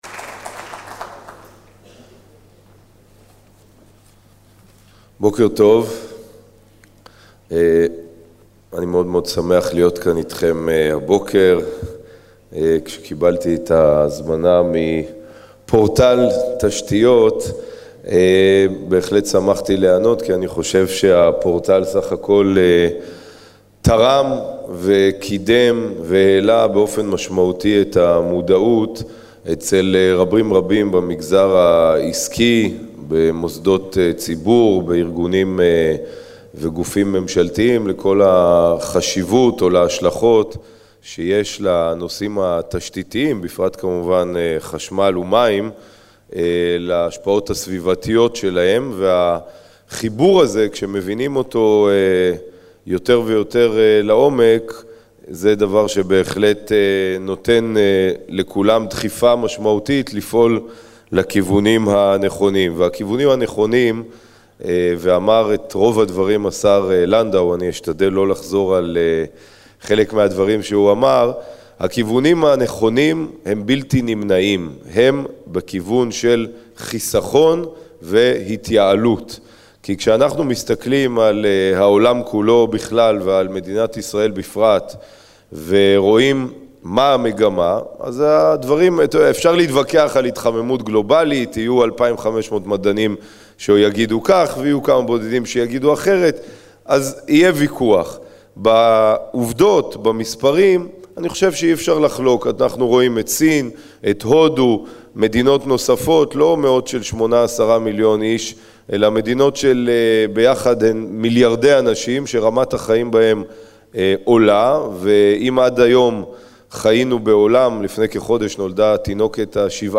השר להגנת הסביבה, גלעד ארדן, פתח את כנס פורטל תשתיות להתייעלות מוסדית בהצגת פעילות המשרד בדגש על שילוב במסגרת התקן החדש לבנייה ירוקה.